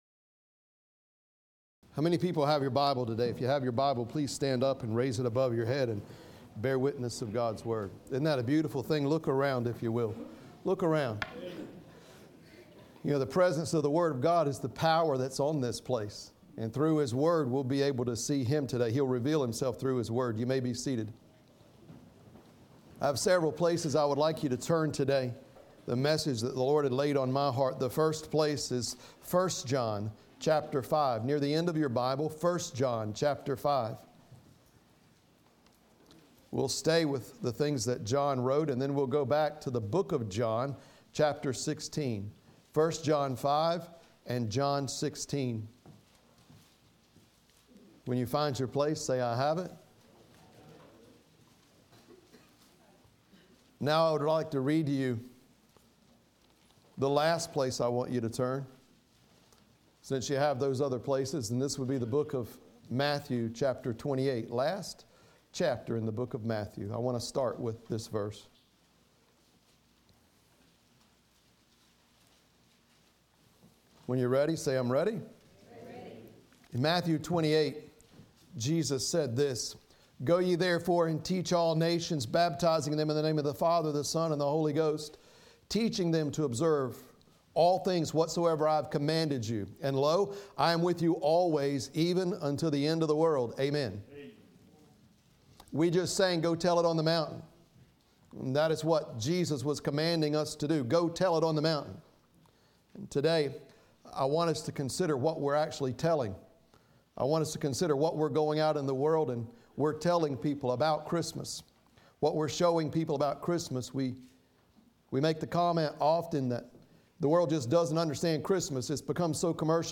Listen to Sermons - Nazareth Community Church